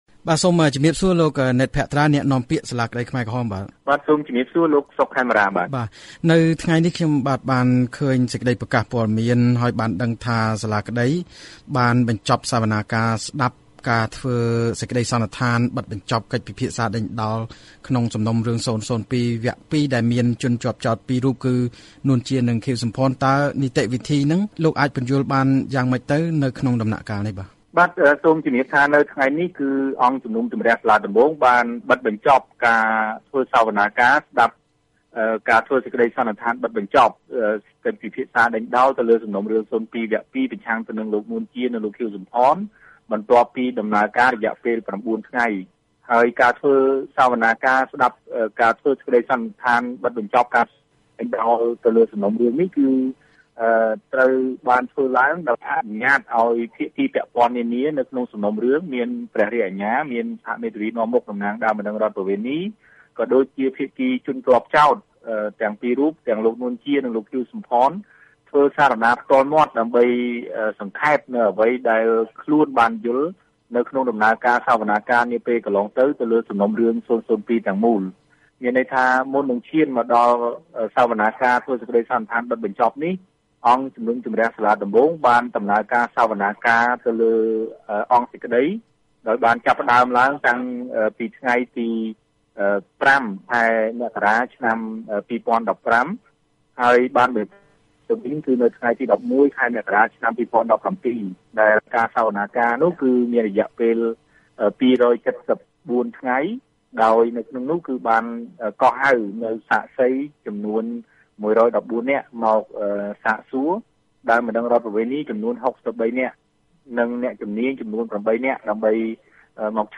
បទសម្ភាសន៍ VOA៖ នួន ជានិងខៀវ សំផនស្ថិតក្រោមការពិចារណាដាក់ទោសទណ្ឌក្រោយបិទបញ្ចប់សវនាការវគ្គចុងក្រោយ